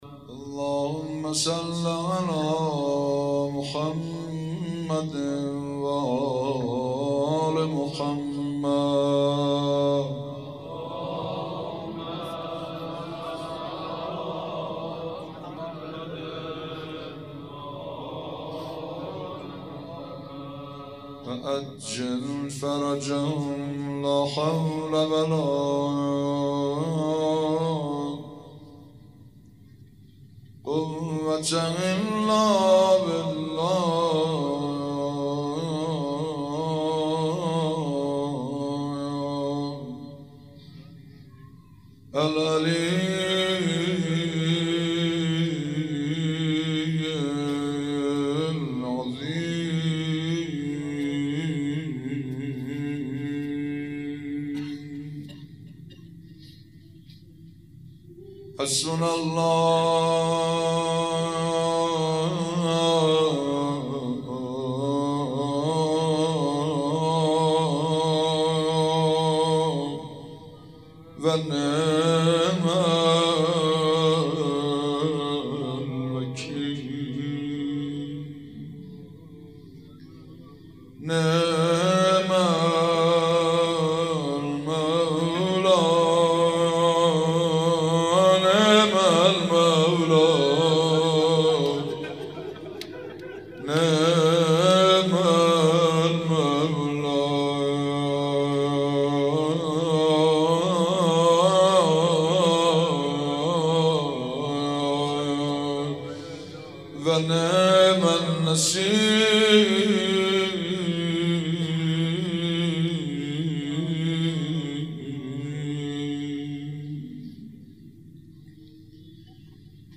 روضه و مناجات
روضه محمود کریمی